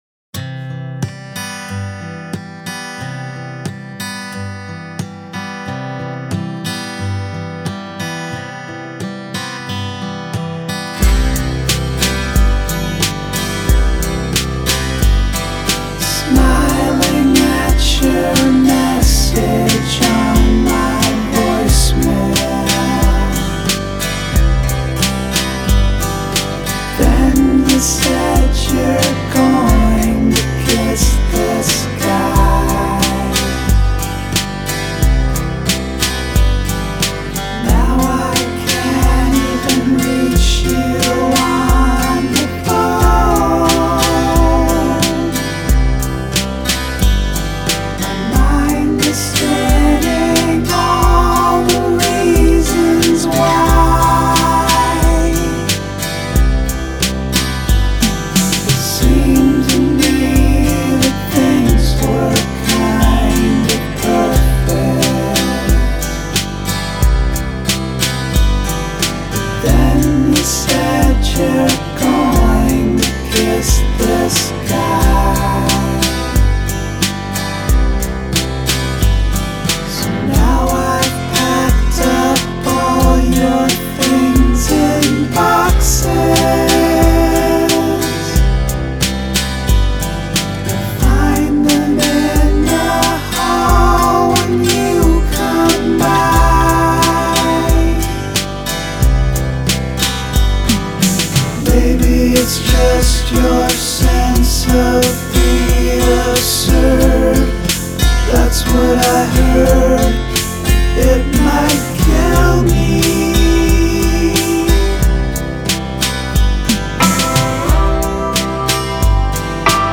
Nice work but very repetitious without much dynamics.